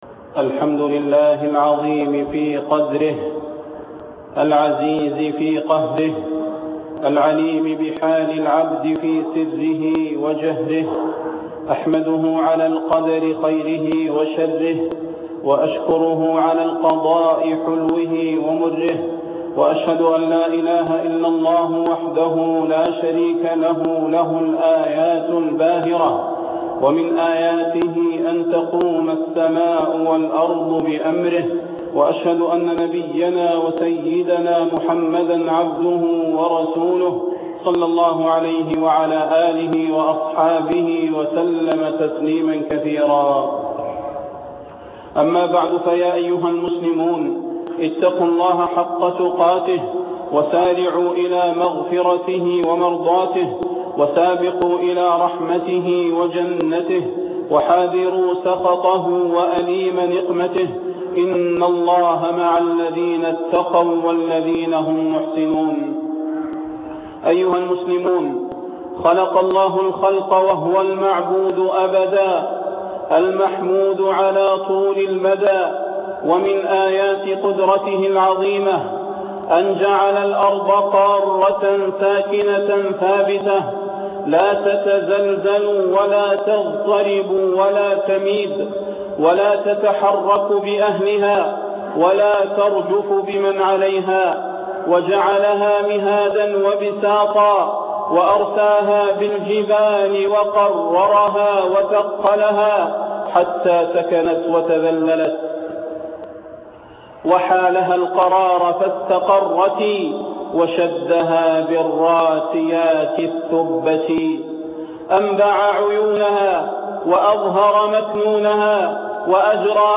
Ansarallah Ø®Ø·Ø¨Ø© Ø§Ù„ØØ±Ù… Ø§Ù„Ù…Ø¯Ù†ÙŠ26/5/1430